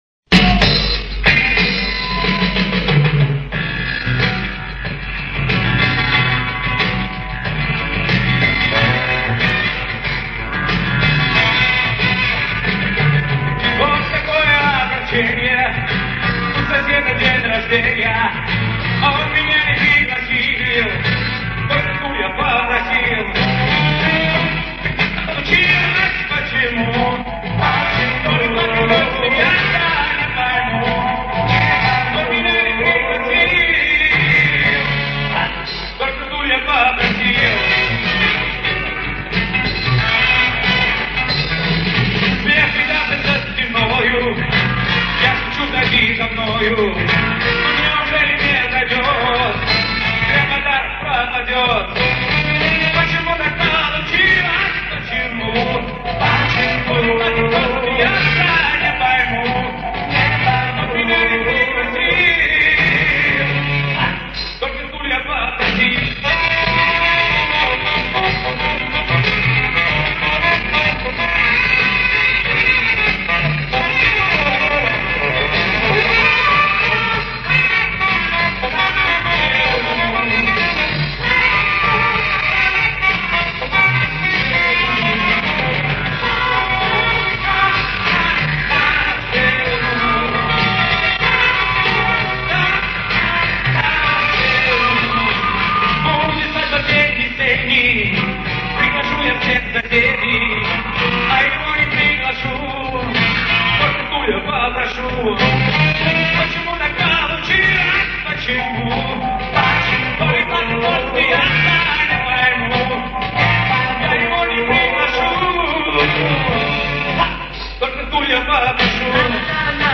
ПЕСНЯ